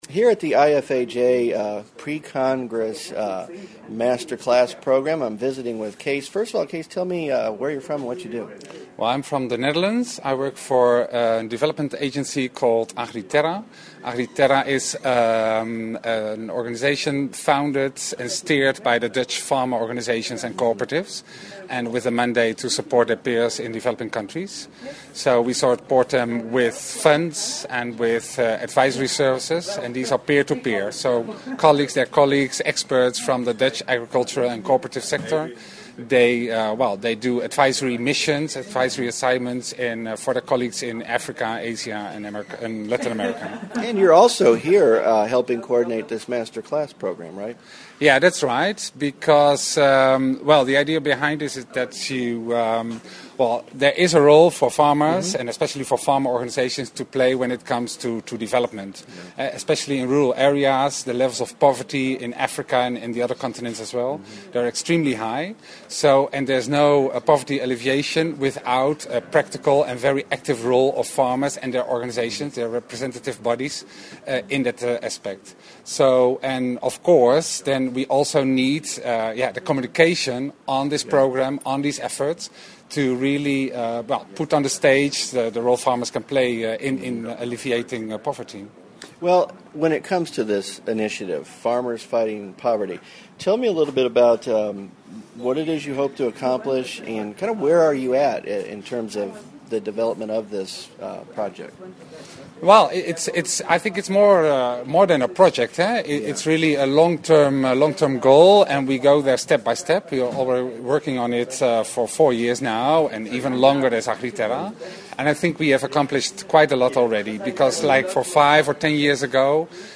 2011 IFAJ Congress Photo Album